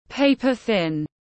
Mỏng như tờ giấy tiếng anh gọi là paper-thin, phiên âm tiếng anh đọc là /ˈpeɪ.pəˌθɪn/ .
Paper-thin /ˈpeɪ.pəˌθɪn/